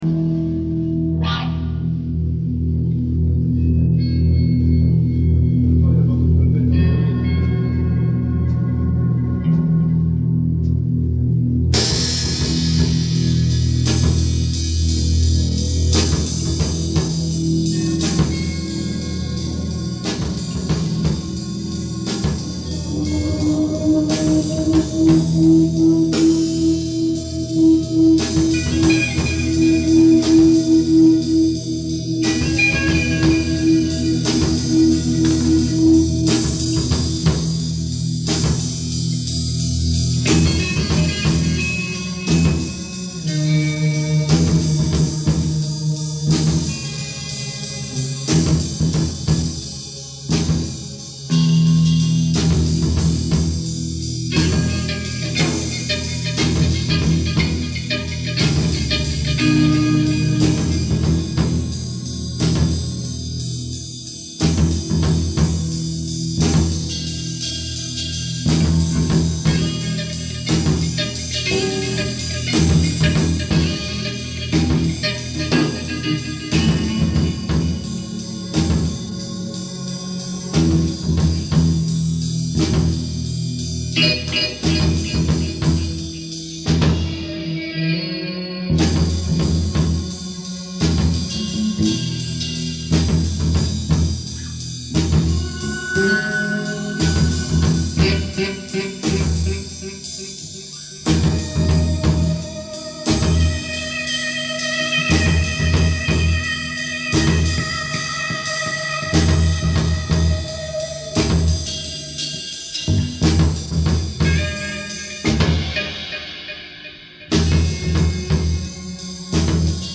Drum
Basse
Guitar and samples
Les 3 titres sont improvis�s en MAI 2003 dans un petit garage.